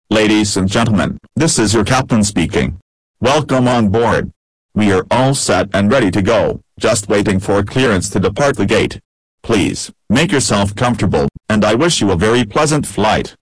cpt_welcome.wav